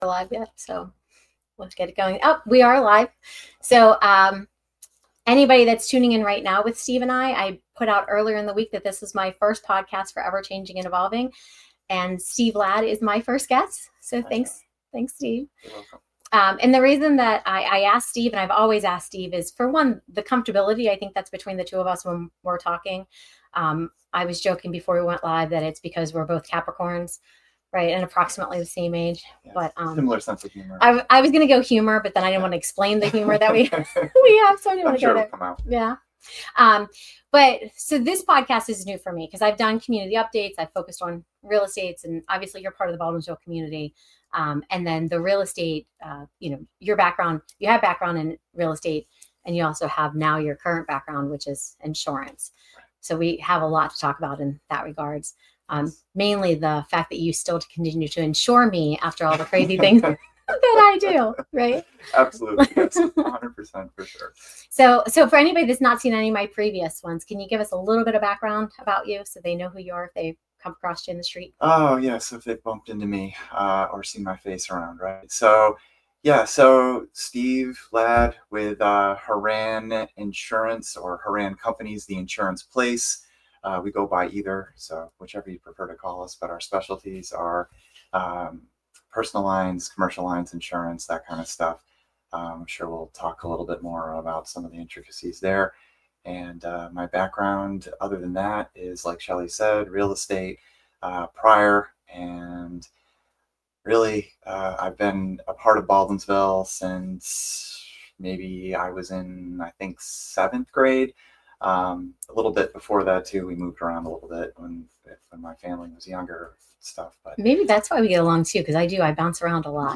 This conversation sets the tone for the journey ahead: honest stories, real lessons, and plenty of laughs along the way.